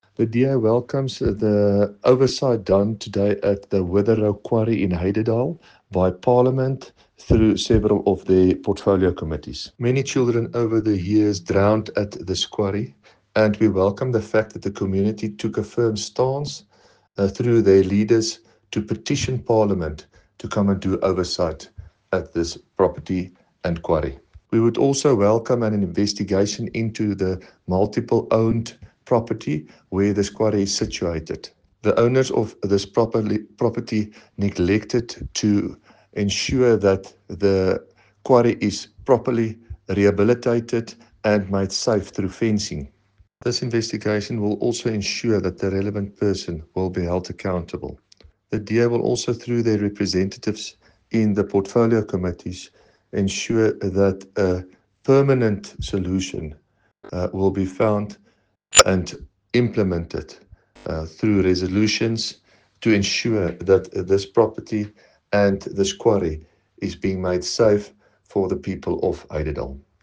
Afrikaans soundbites by David van Vuuren MPL, and image here